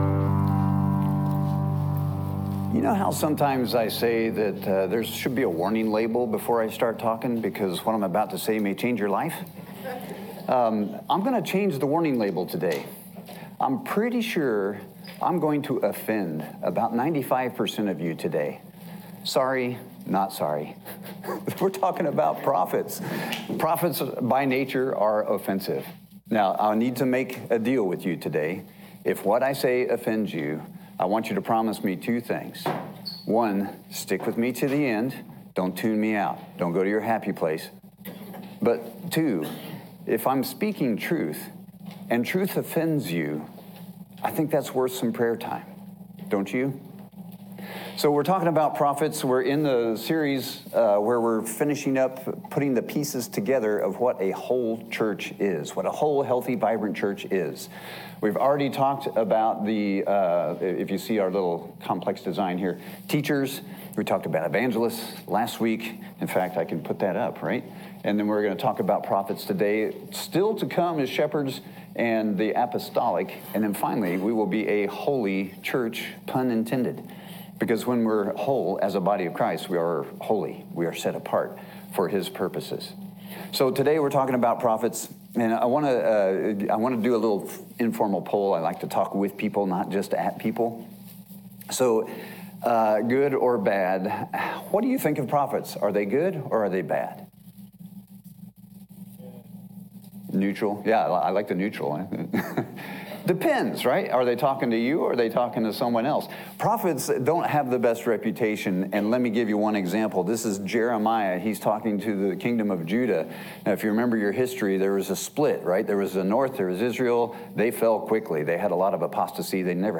Sermons
audio-sermon-prophetic-1.m4a